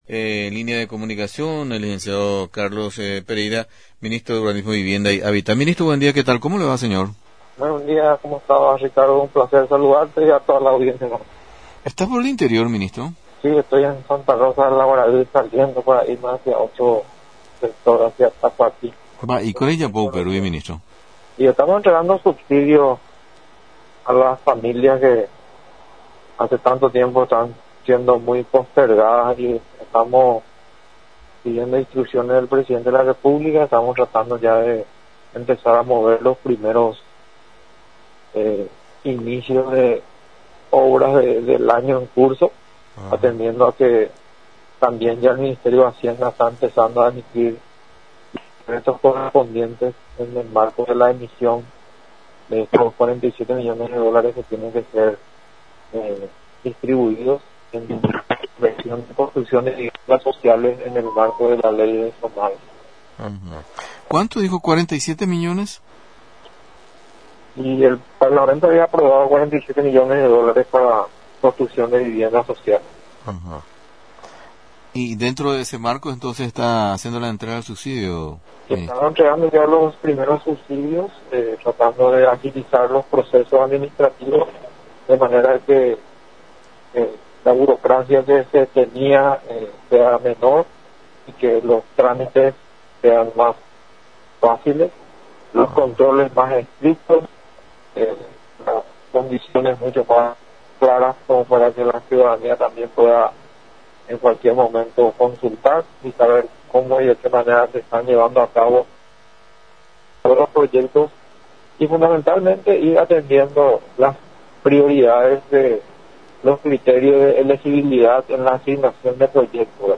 El Ministro de la cartera Carlos Pereira, dijo a Radio Nacional del Paraguay, que está entregando subsidio a familias que hace tiempo están postergadas, son 86 familias de Santa Rosa del Aguaray, 73 en Tacuatí y 61 en Coronel Oviedo.